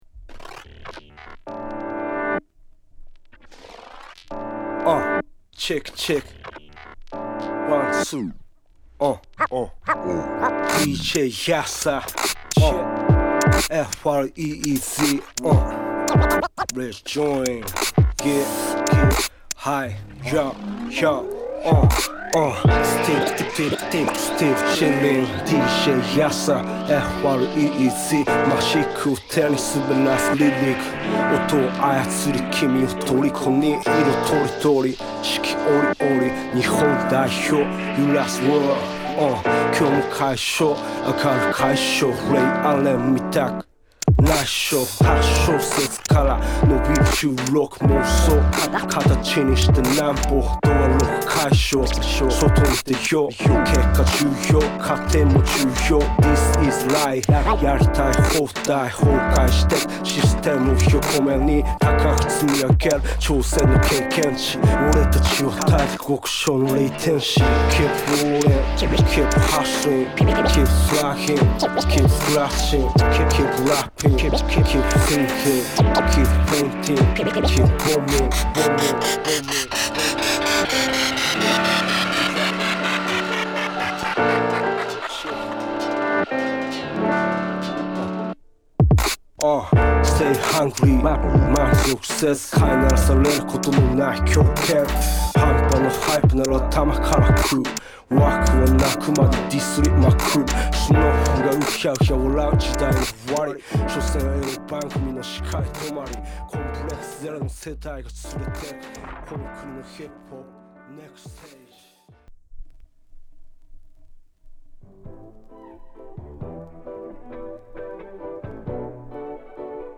メロウなピアノが響くSideAのオリジナルに